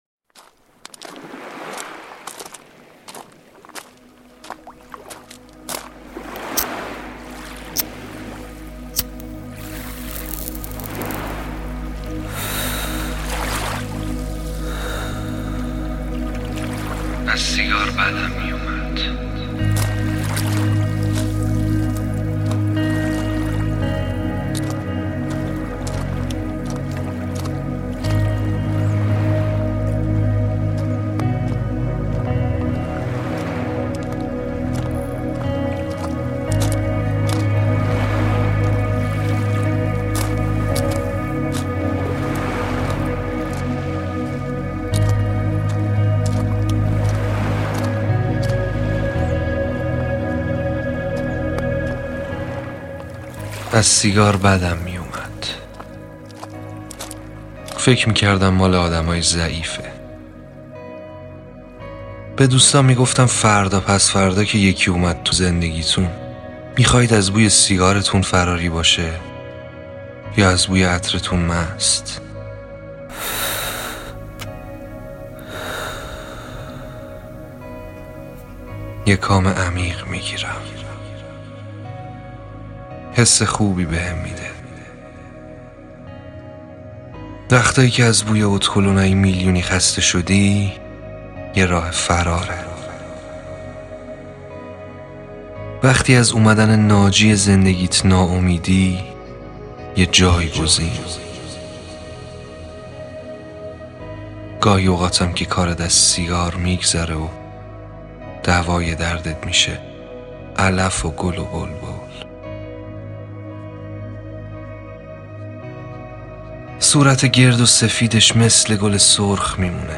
داستان صوتی سیگار
زیبا، احساسی و عاشقانه